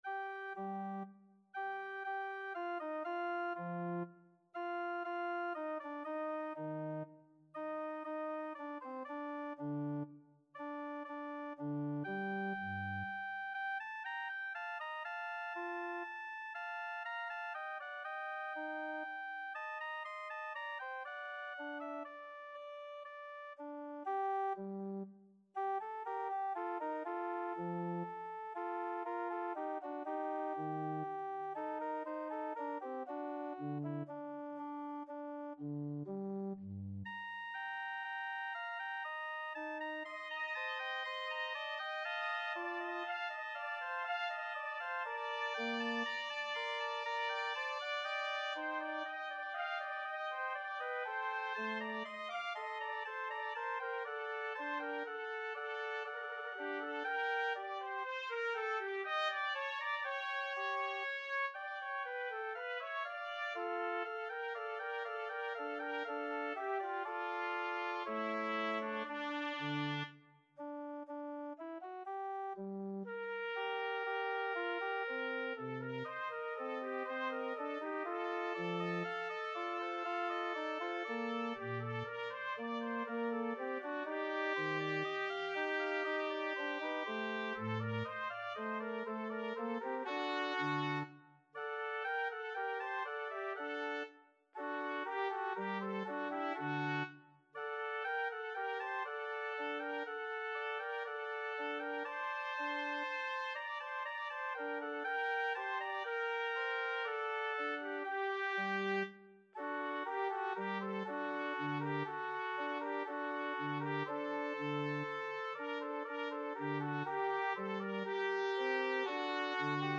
3/4 (View more 3/4 Music)
D5-C7
Classical (View more Classical Trumpet Music)